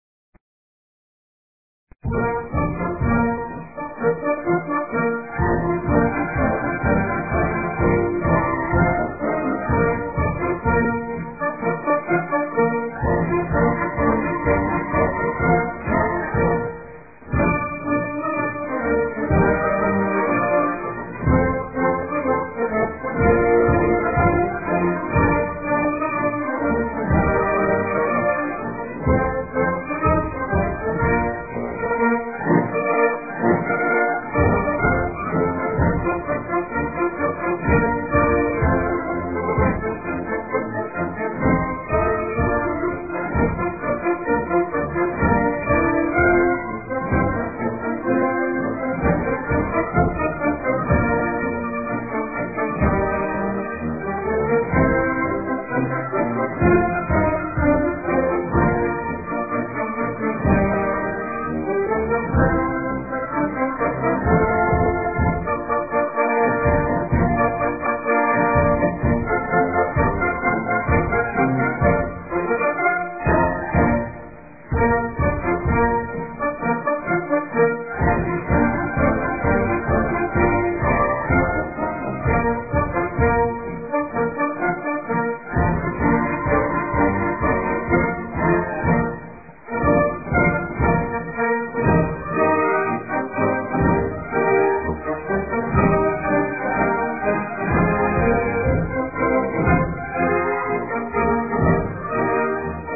Ce chant militaire , a sans doute vu le jour en 1915
C'était une marche de l'armée d'Afrique qui a pris son essor avec la musique du  Capitaine Félix BOYER, Chef de la Musique Régionale des Chantiers de Jeunesse d'Afrique du Nord.